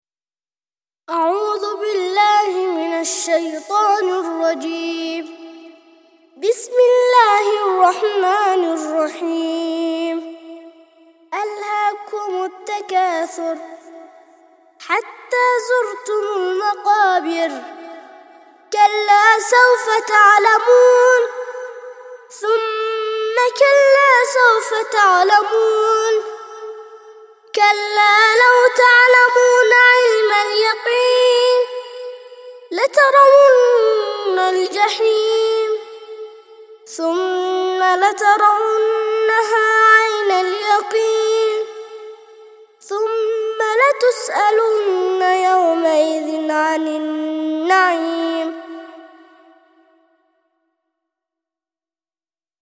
102- سورة التكاثر - ترتيل سورة التكاثر للأطفال لحفظ الملف في مجلد خاص اضغط بالزر الأيمن هنا ثم اختر (حفظ الهدف باسم - Save Target As) واختر المكان المناسب